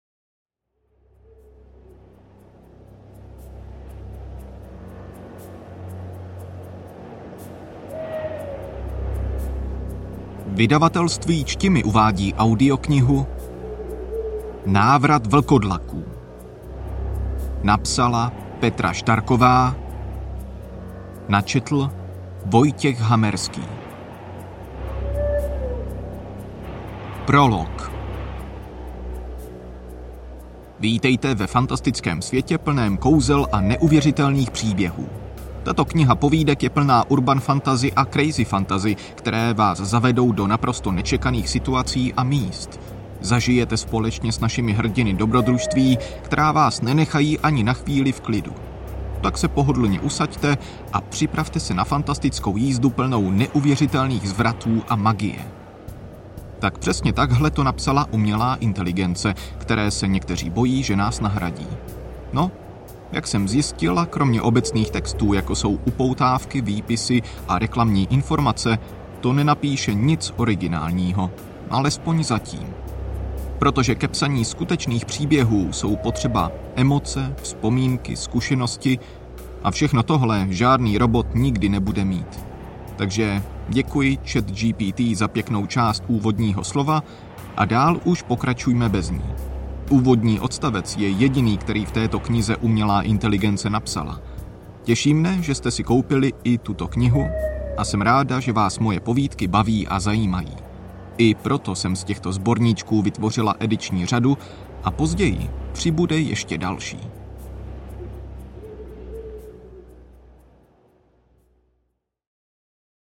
Každý uživatel může po zakoupení audioknihy daný titul ohodnotit, a to s pomocí odkazu zaslaný v mailu.